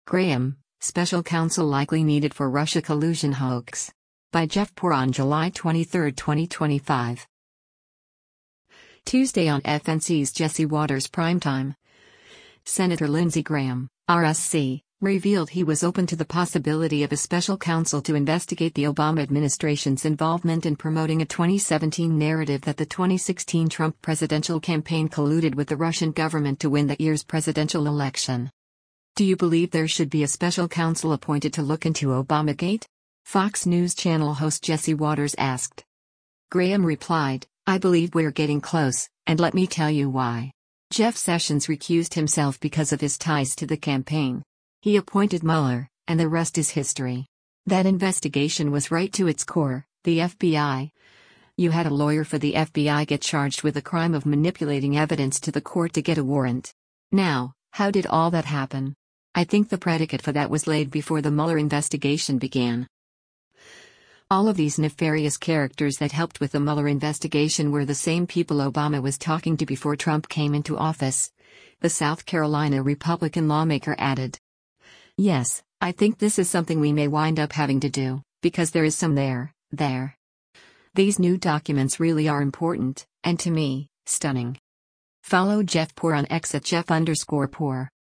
“Do you believe there should be a Special Counsel appointed to look into Obamagate?” Fox News Channel host Jesse Watters asked.